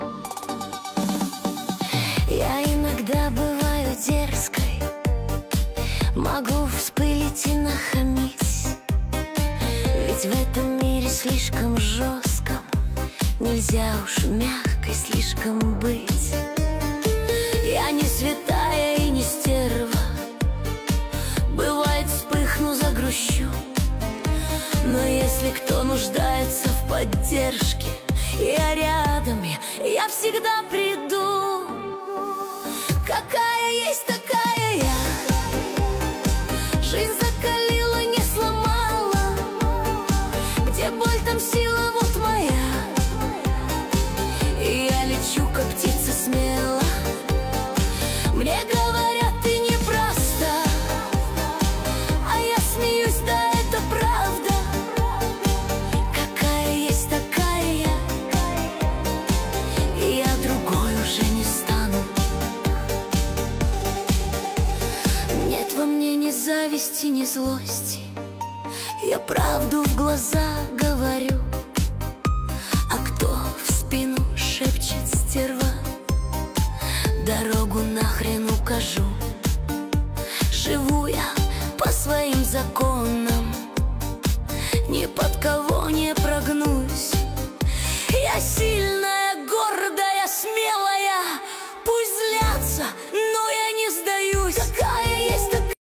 Нейросеть Песни 2025, Стихи